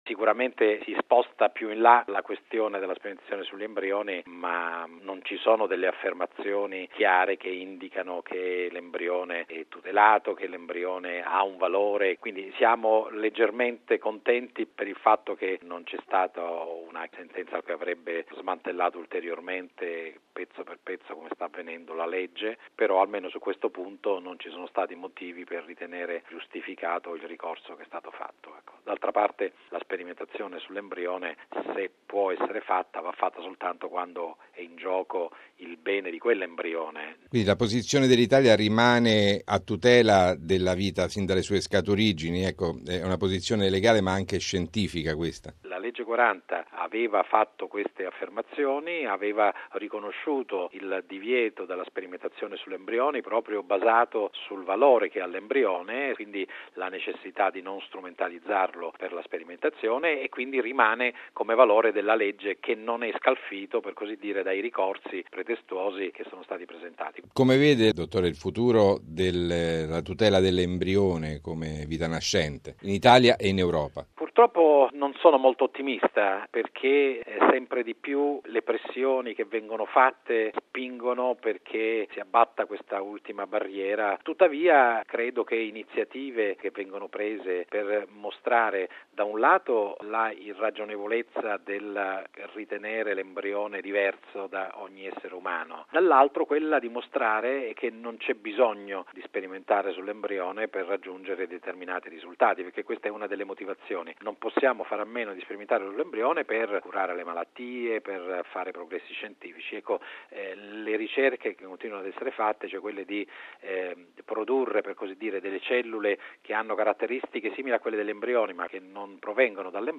genetista